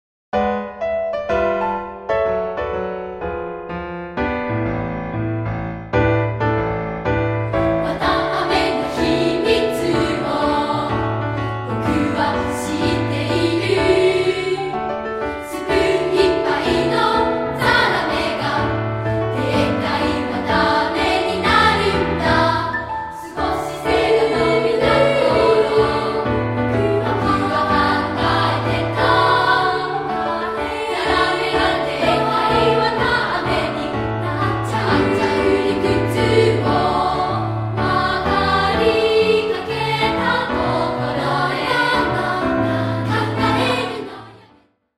2部合唱／伴奏：ピアノ